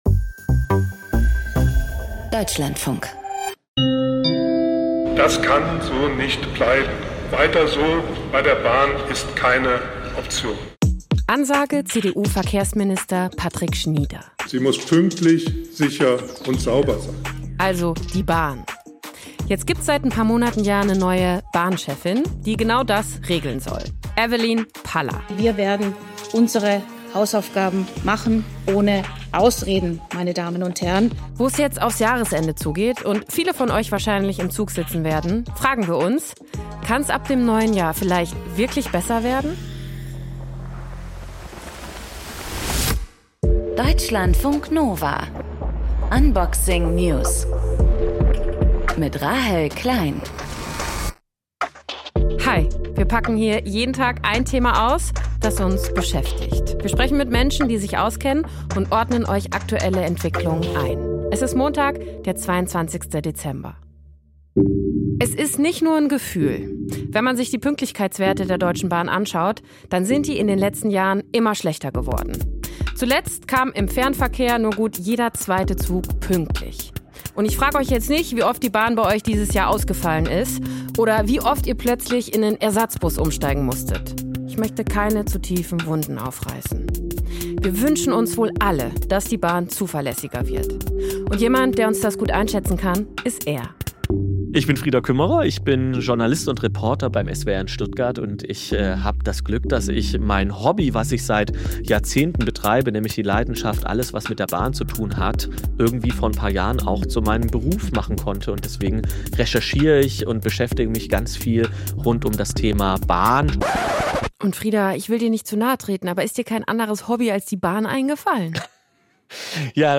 Bahn-Experte und SWR-Journalist Gesprächspartner